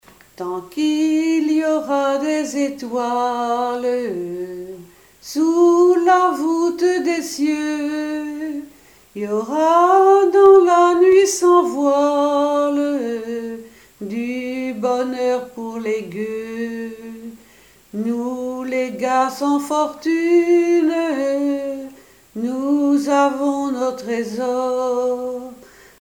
Une chanson de 1936
Genre strophique
Chansons et commentaires
Pièce musicale inédite